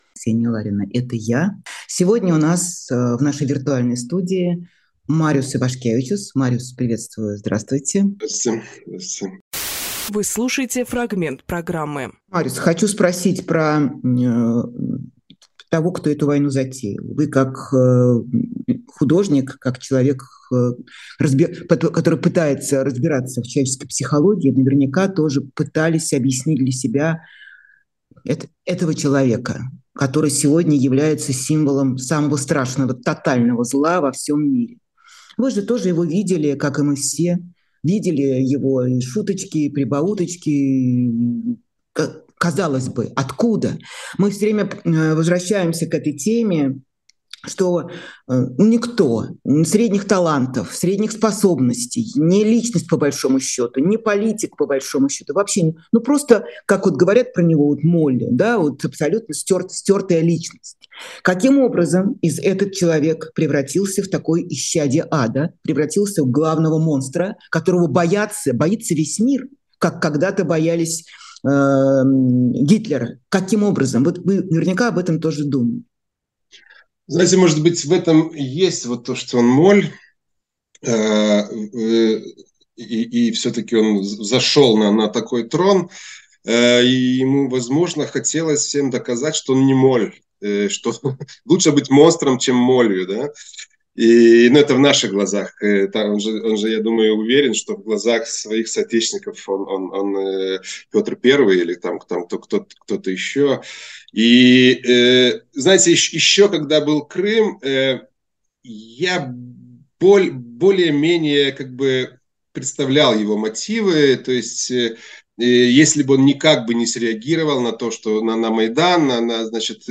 Фрагмент эфира от 17.06.23